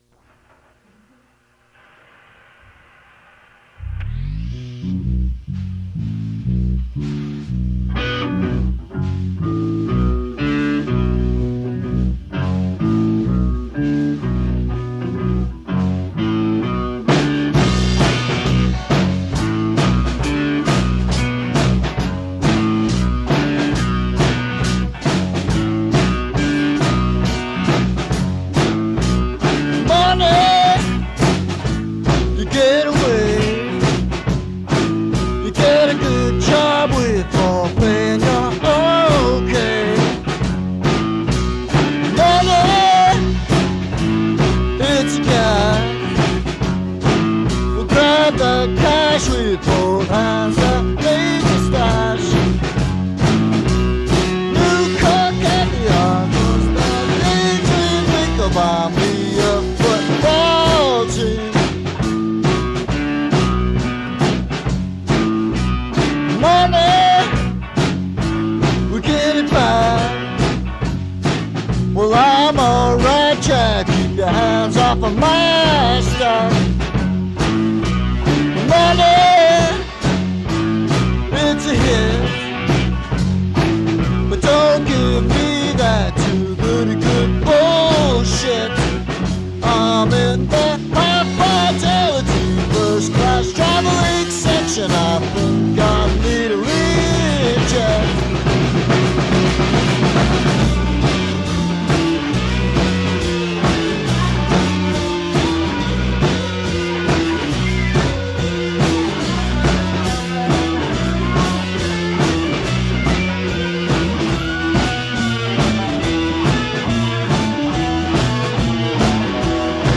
Fait partie de Acoustic reinterpretation of rock music